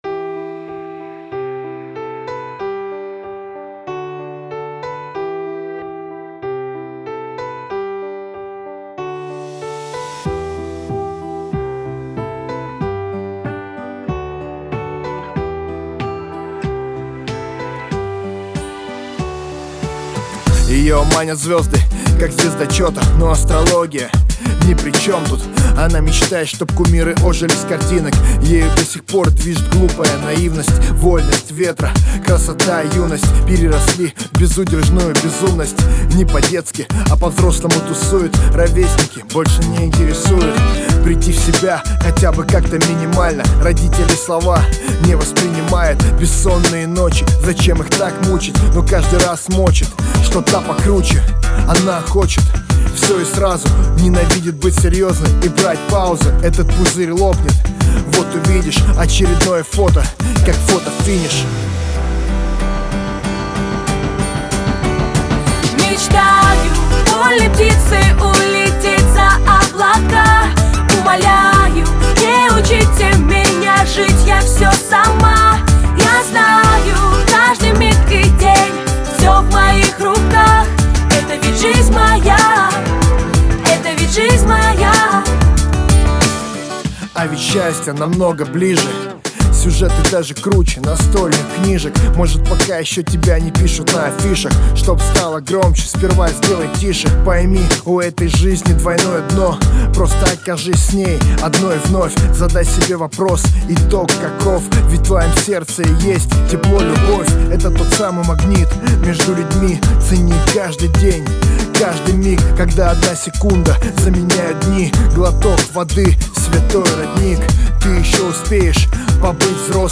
Категория: Рэп